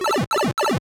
Sound effect of "Pipe Travel / Power-Down" in Super Mario Bros. Deluxe.
SMBDX_Power_Down.oga